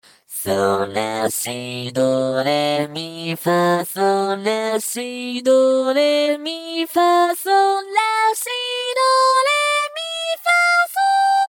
性別：女
VOICEBANK
收錄音階：D4 G4